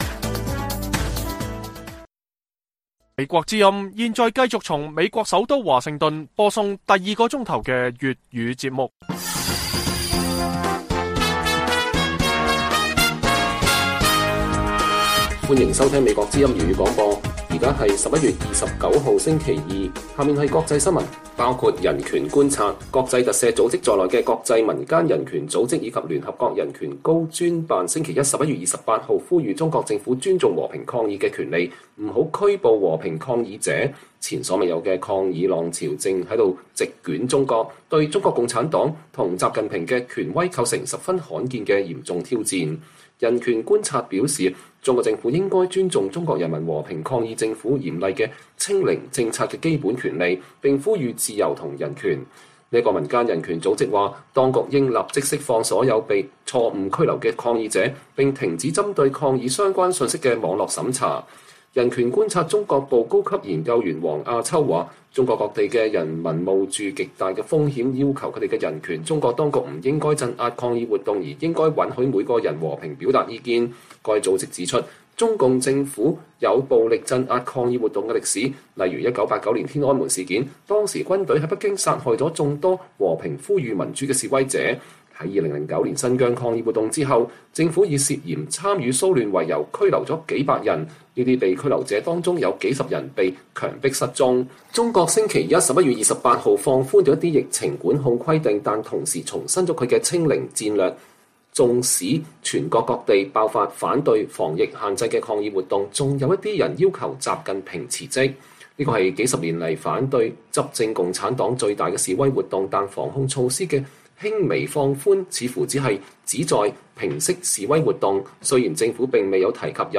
粵語新聞 晚上10-11點：國際人權組織呼籲中國政府尊重和平抗議權利